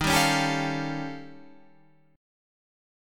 D# 9th Flat 5th